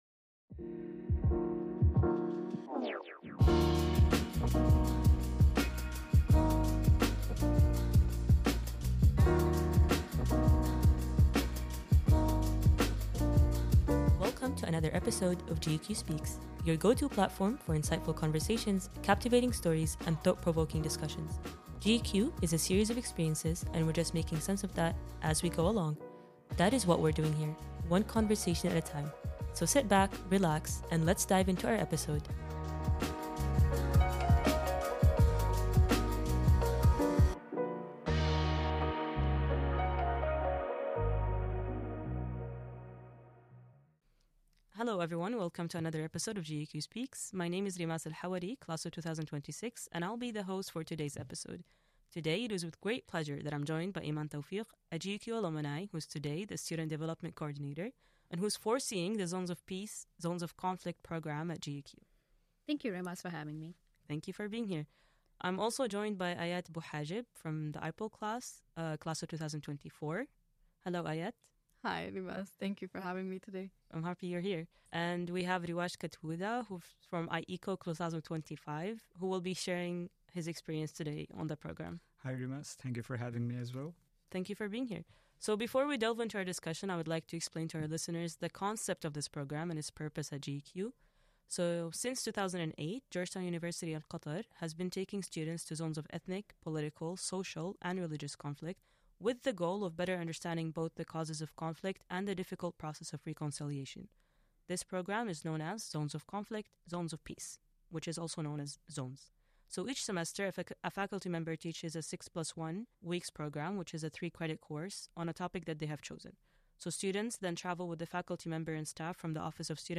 In this episode, podcast hosts discuss the Zones of Peace Zones of Conflict program offered at GU-Qatar and its development after COVID-19.